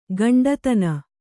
♪ gaṇḍa tana